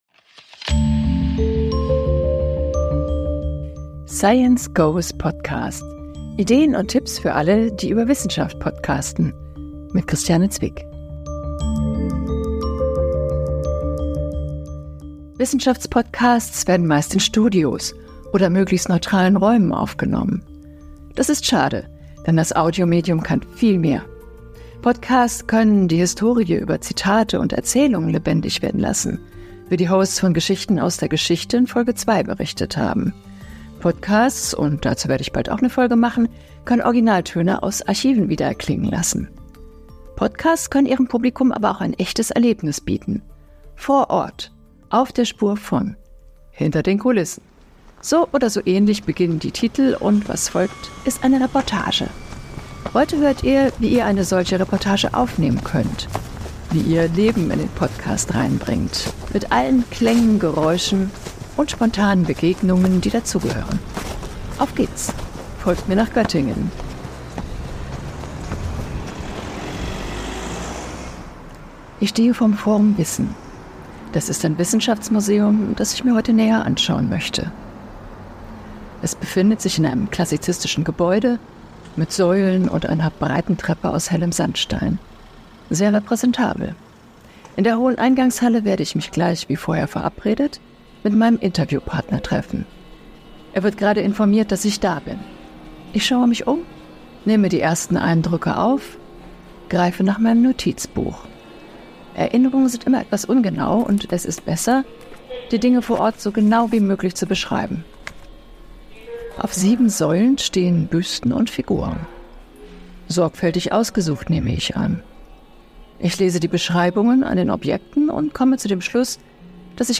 Diese Folge von „Science goes Podcast“ zeigt, wie eine Reportage aufgenommen wird. Mit allen Geräuschen und spontanen Begegnungen, die dazu gehören. Wir besuchen das Forum Wissen, das für den European Museum of the Year Award 2024 nominiert ist.